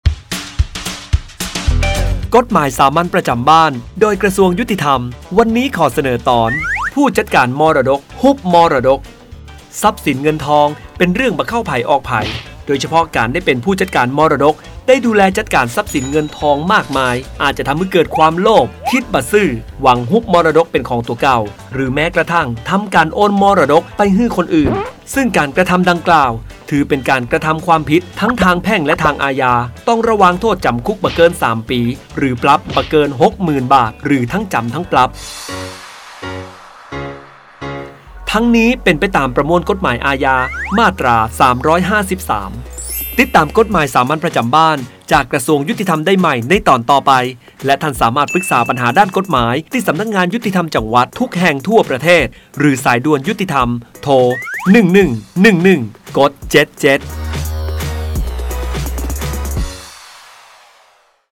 ชื่อเรื่อง : กฎหมายสามัญประจำบ้าน ฉบับภาษาท้องถิ่น ภาคเหนือ ตอนผู้จัดการมรดก ฮุบมรดก
ลักษณะของสื่อ :   บรรยาย, คลิปเสียง